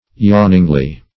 yawningly - definition of yawningly - synonyms, pronunciation, spelling from Free Dictionary Search Result for " yawningly" : The Collaborative International Dictionary of English v.0.48: Yawningly \Yawn"ing*ly\, adv.
yawningly.mp3